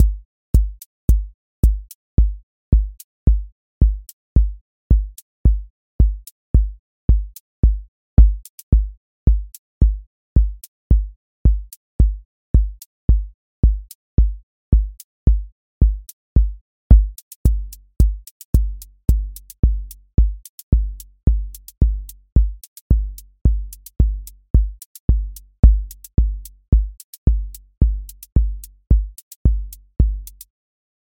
QA Listening Test house Template: four_on_floor
steady house groove with lift return
• voice_kick_808
• voice_hat_rimshot
• voice_sub_pulse